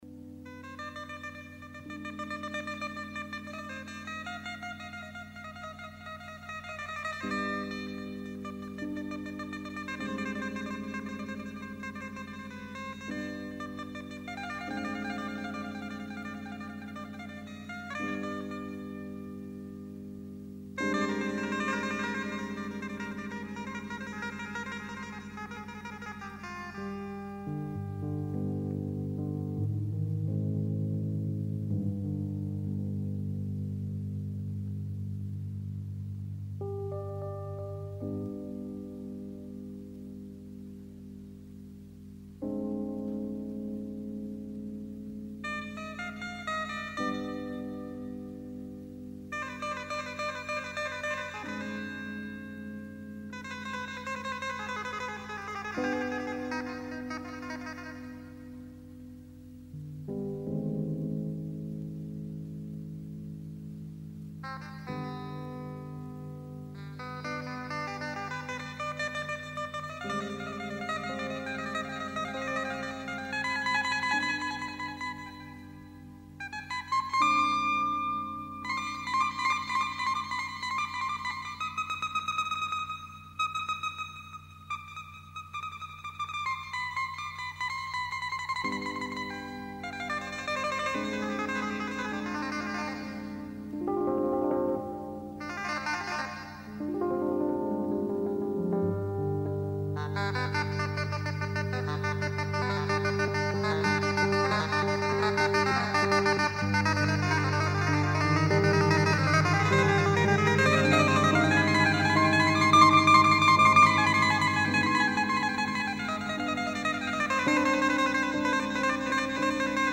Very obscure Austrian jazz album
a Spanish / Arabic flamenco jazz piece